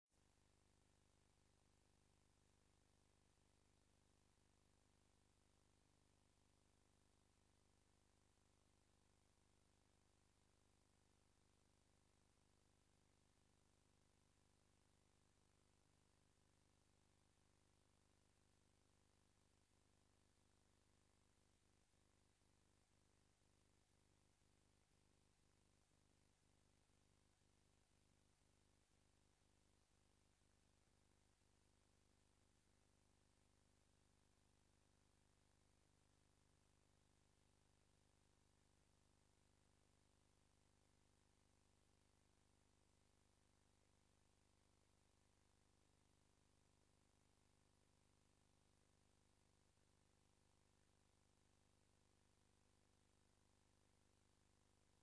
Raadscommissie Stadsontwikkeling en Beheer 23 maart 2015 19:30:00, Gemeente Den Helder
Raadzaal